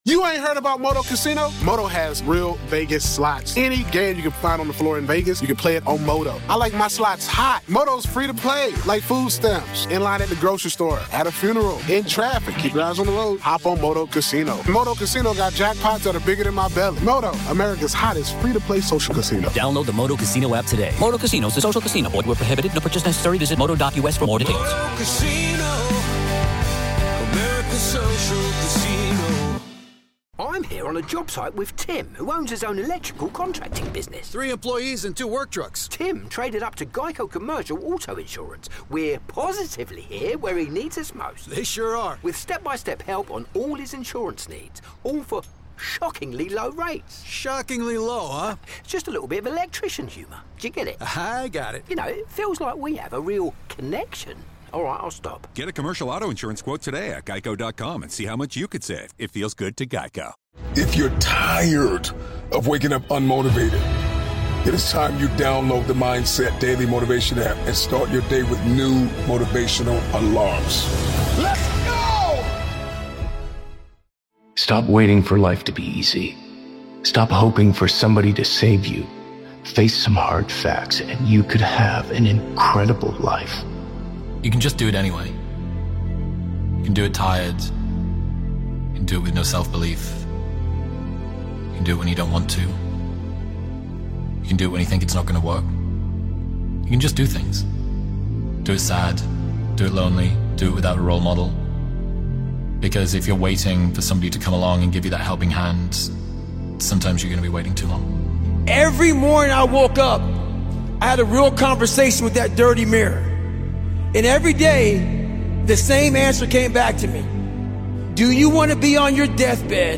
It's up to YOU to envision and create the life you want for yourself. Best Motivational Speeches Compilation featuring Chris Williamson, Mel Robbins, Ed Mylett, David Goggins, and more.